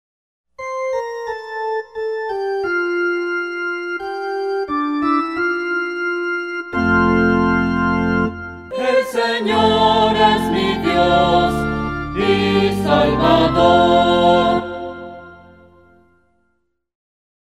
SALMO RESPONSORIAL Isaías 12 R. El Señor es mi Dios y salvador.